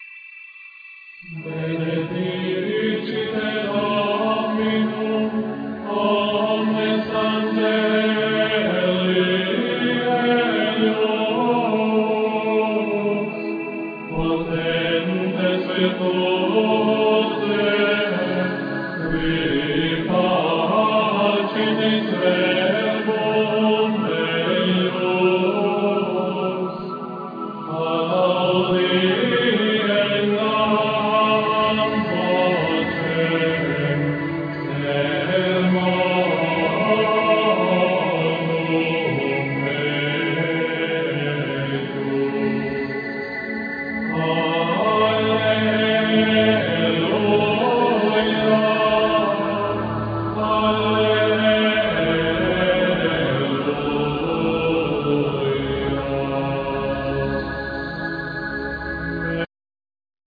Vocals
Keyboards